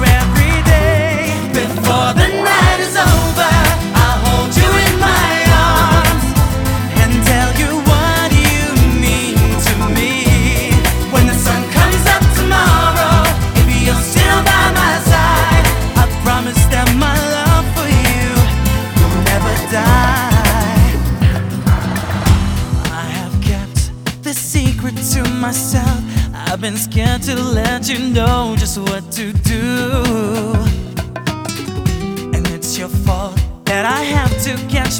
Жанр: Танцевальные / Рок